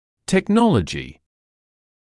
[tek’nɔləʤɪ][тэк’нолэджи]технология